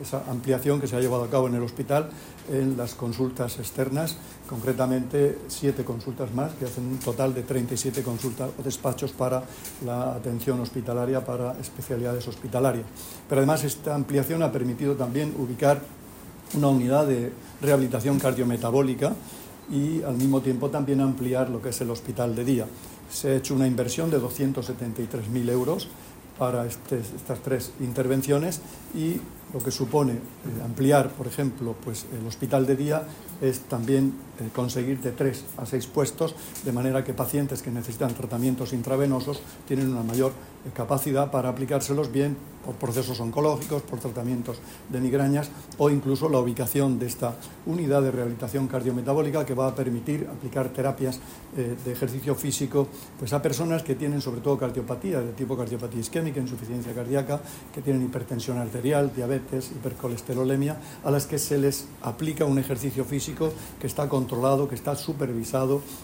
Sonido/ Declaraciones del consejero de Salud, Juan José Pedreño, durante su visita hoy a las nuevas instalaciones del hospital de Yecla.